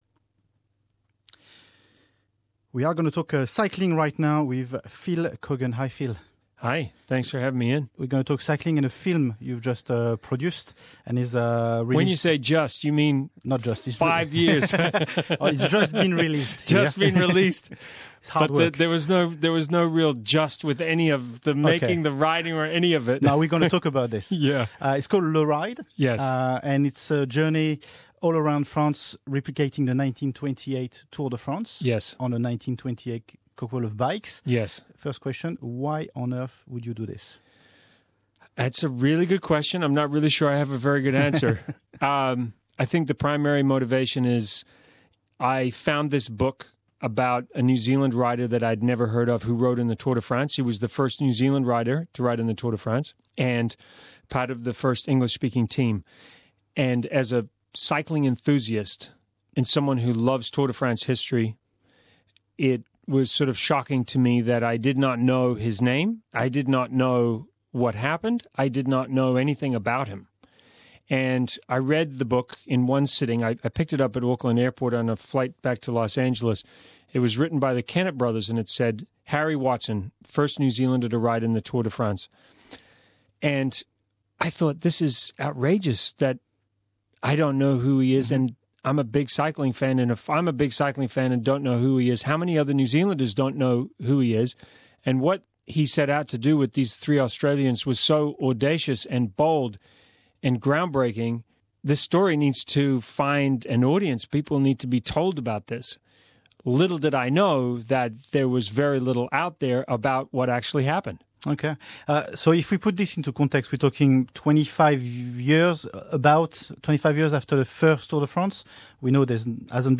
Phil came to see us in studio to talk about the documentary.